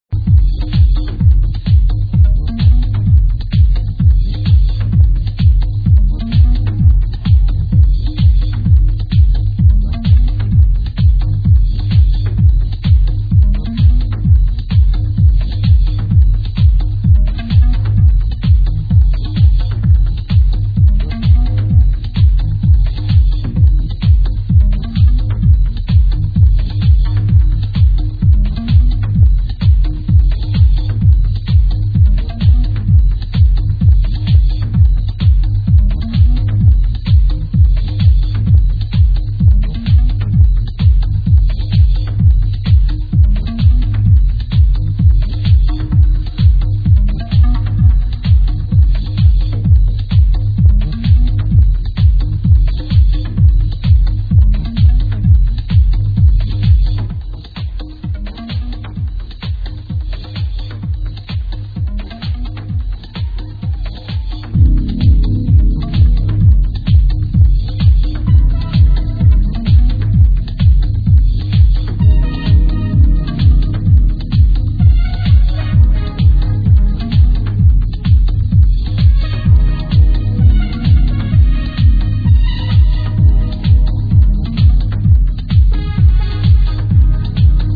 at Mayday 2002
I think this is what they call minimal techno.
Sorry for the quality!